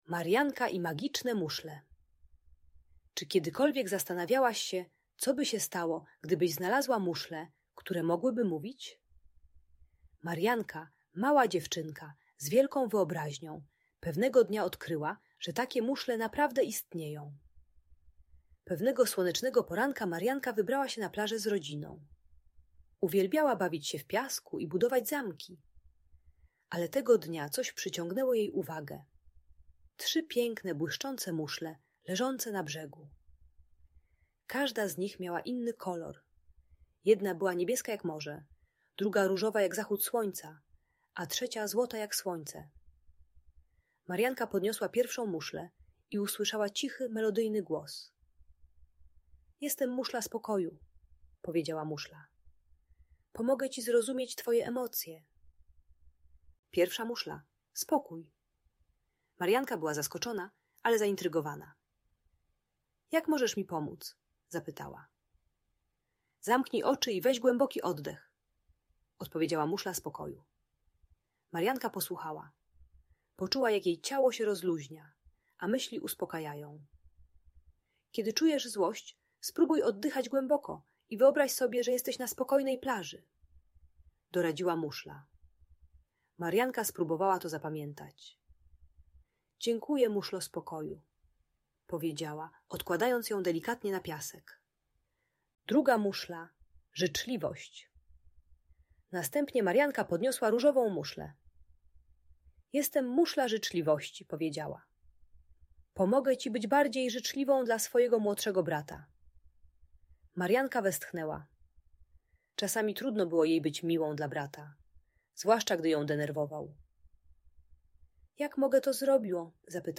Marianka i Magiczne Muszle - Bunt i wybuchy złości | Audiobajka